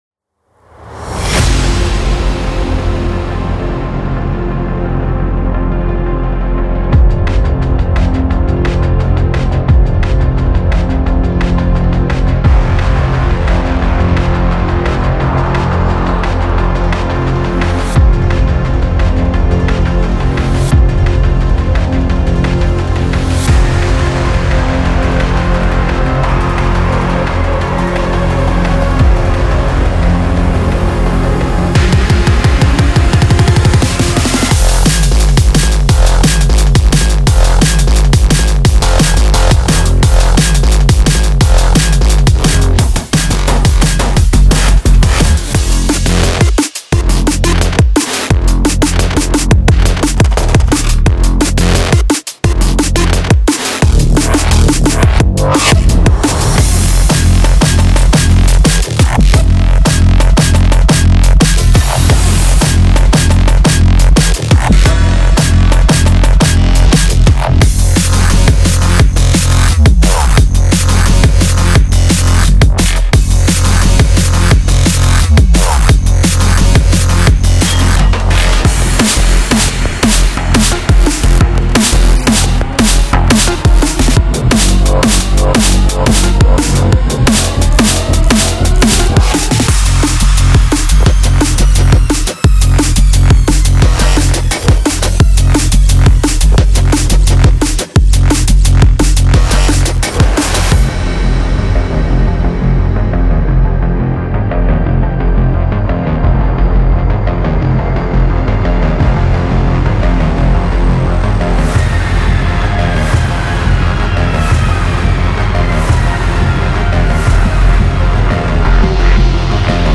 到目前为止，您已经可以找到现成的想法，包括入门，嘎吱嘎吱的贝司，强劲的架子鼓，锐利的合成器，经典的fx，当然还有单发。
174 BPM
关键提示：F